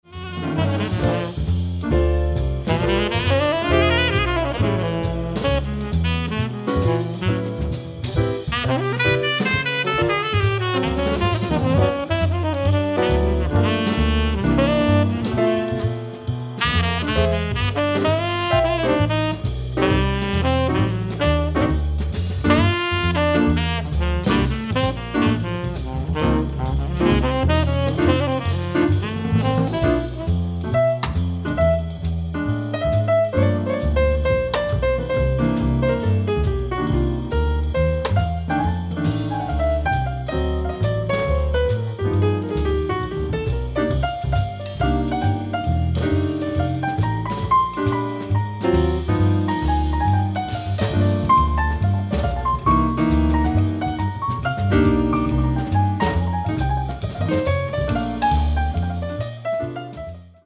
piano
tenor saxophone
acoustic bass
drums